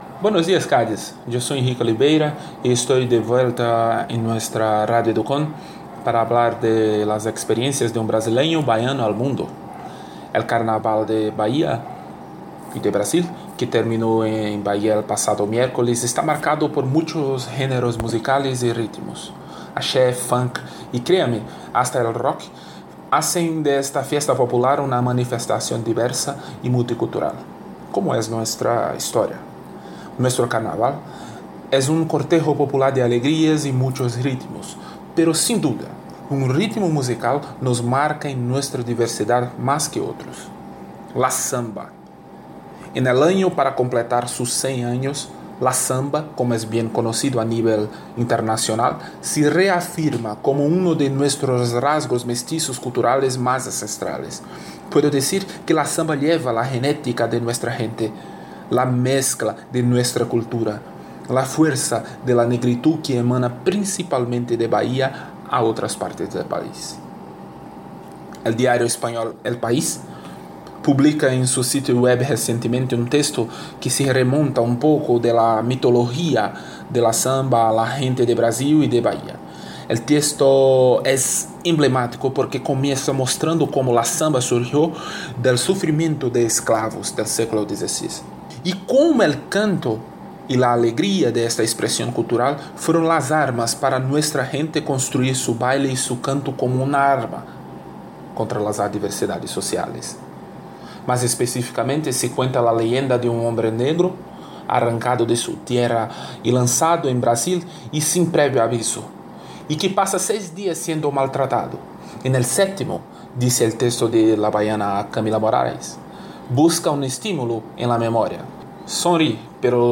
blog hablado